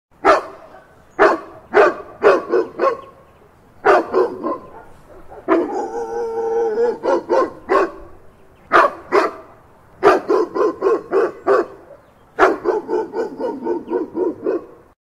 Category : Animals